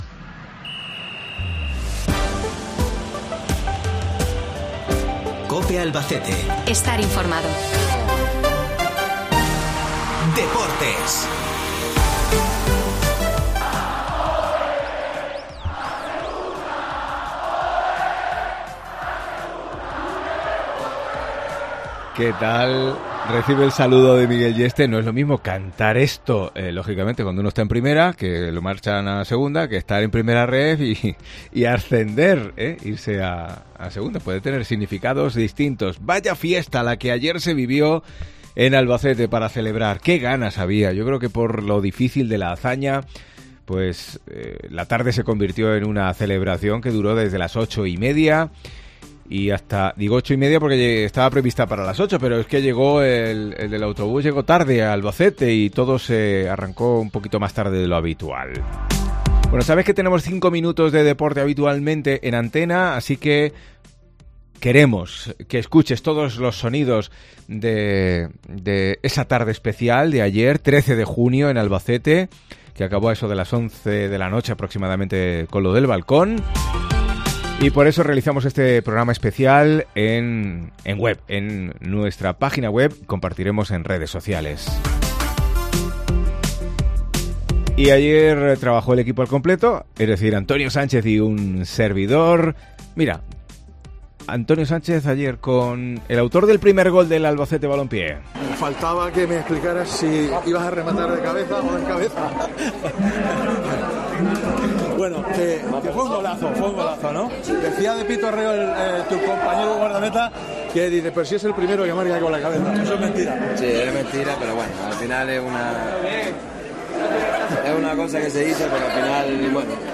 AUDIO: Escucha todos los testimonios de una tarde de fiesta en Albacete